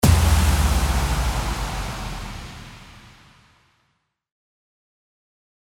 FX-1465-IMPACT
FX-1465-IMPACT.mp3